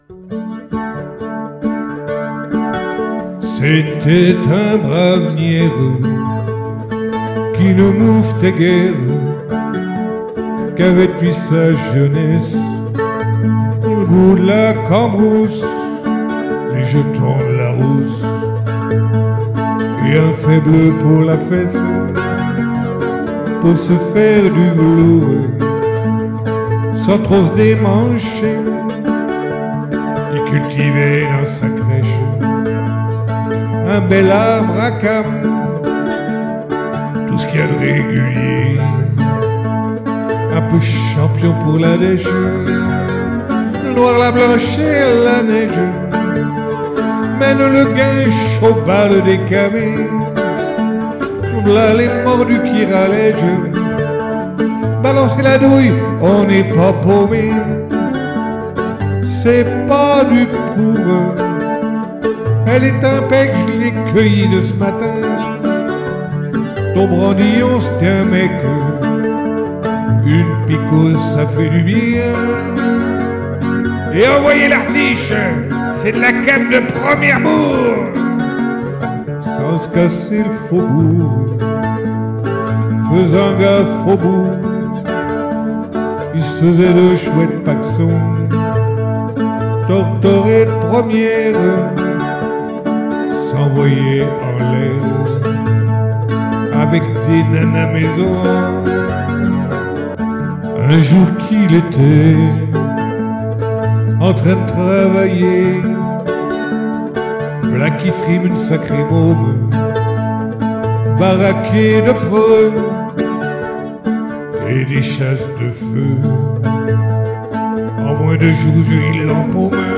[Capo 2°]